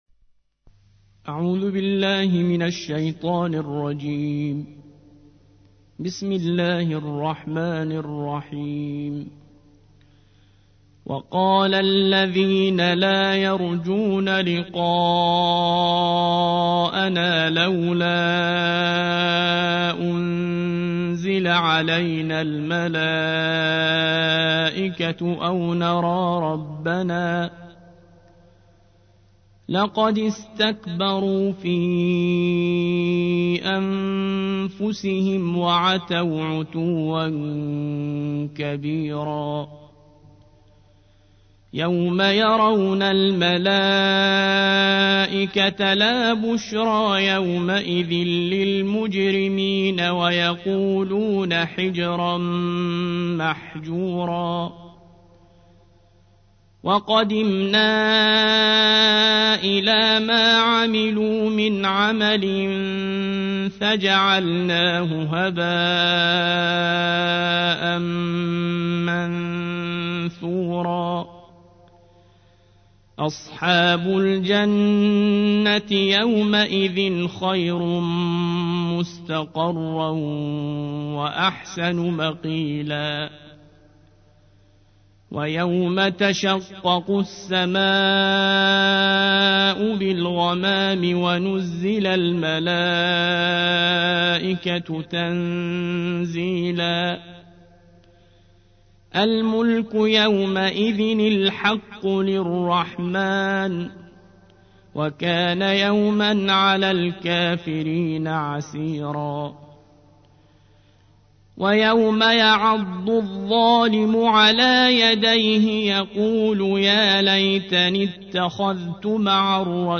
الجزء التاسع عشر / القارئ